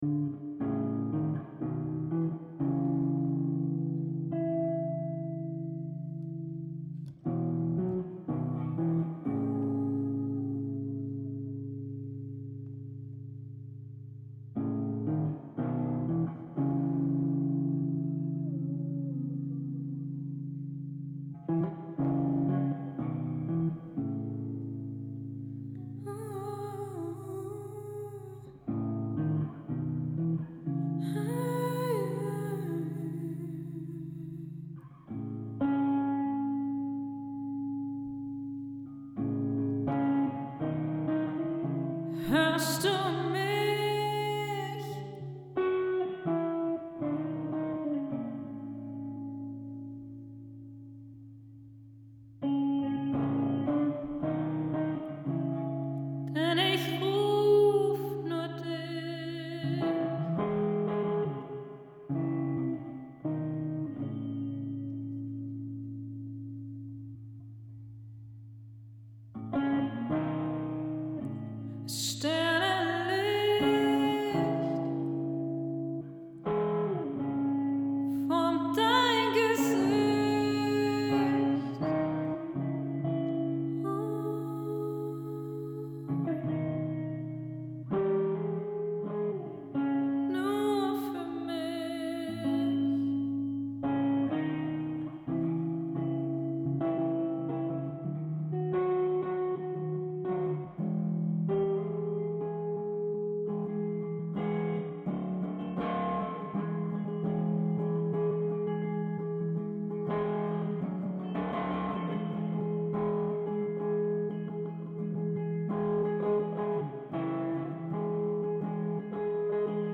jam session song